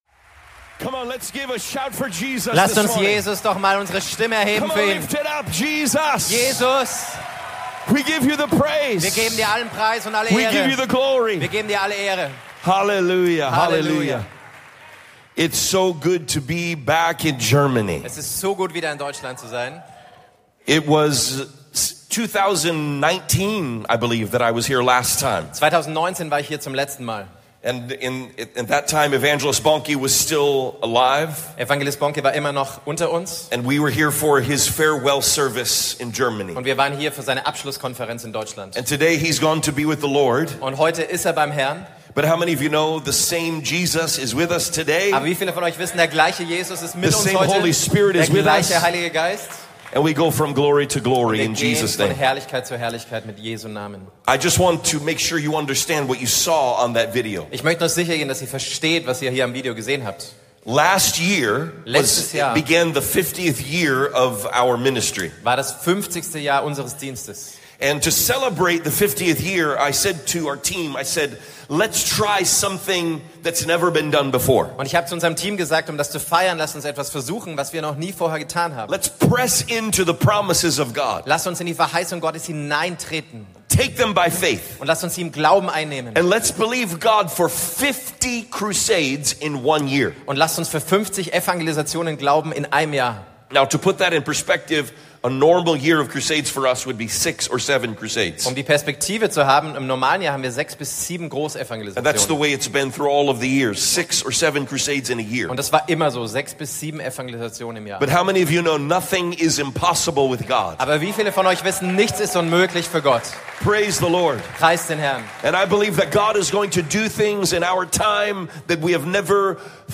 Hier finden Sie alle Predigten der Ecclesia Church